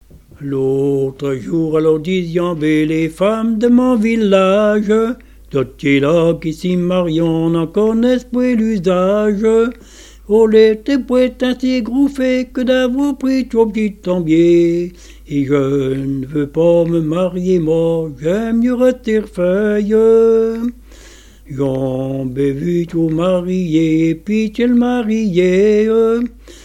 Genre strophique
chansons et témoignes sur les veillées et la musique
Pièce musicale inédite